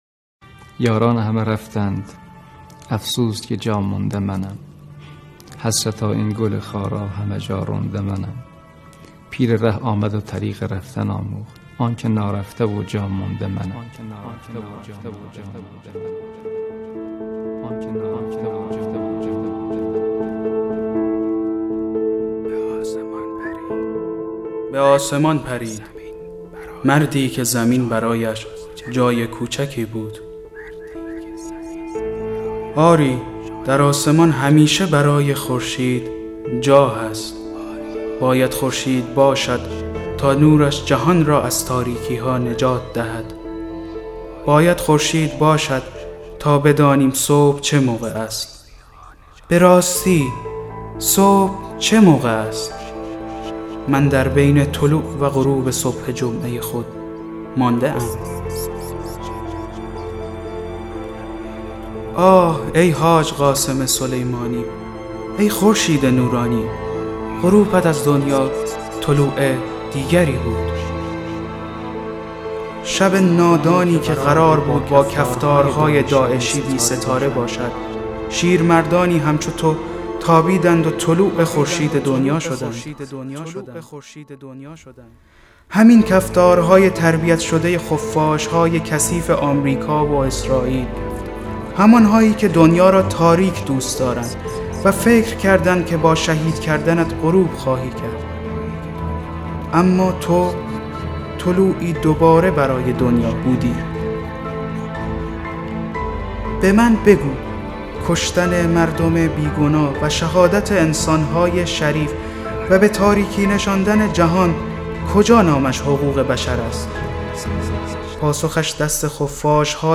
دکلمه طلوع حاج قاسم